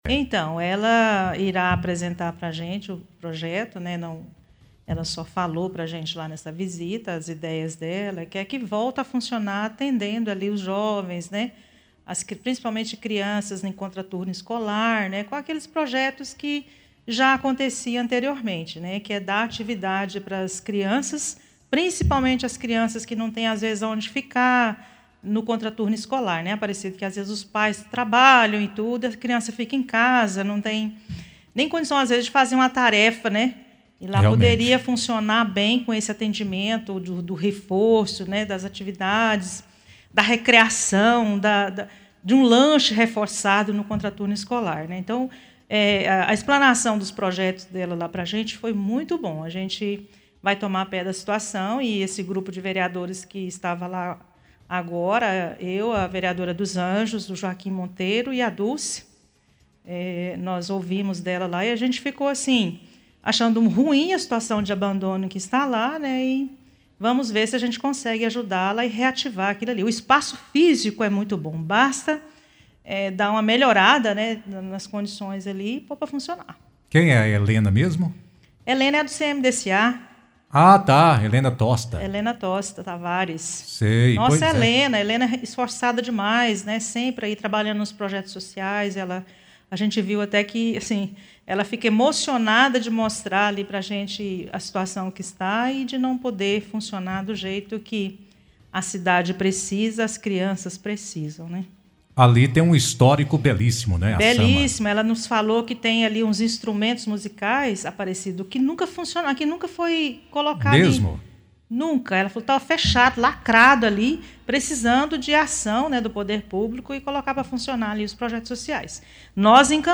A vereadora Maria José é uma entusiasta da reativação da SAMA e informa os procedimentos que estão sendo tomados visando esse fim.
FALA-DA-VEREADORA.mp3